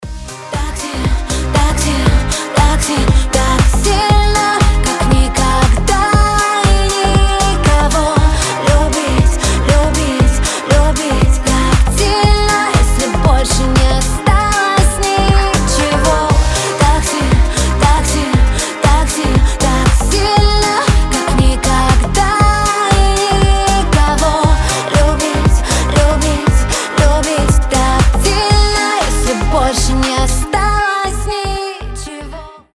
• Качество: 320, Stereo
красивые
женский голос